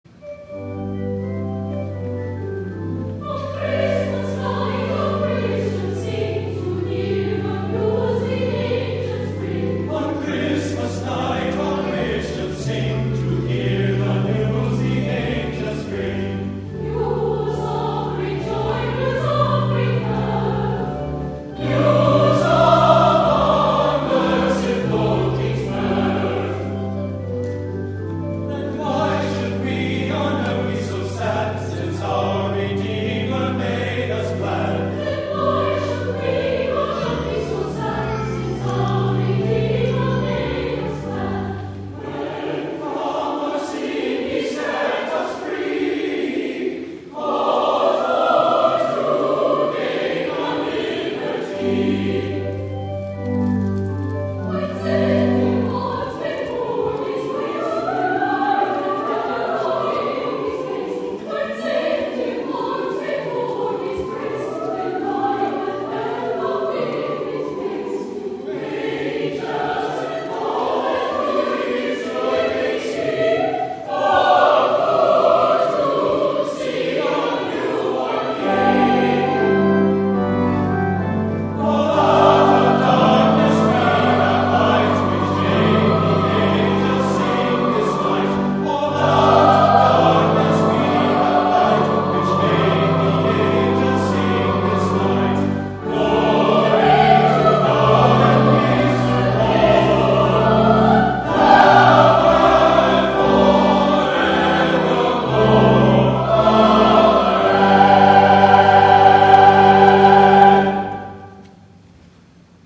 11 A.M. WORSHIP
Sussex Carol  English traditional carol/ arr. David Willcocks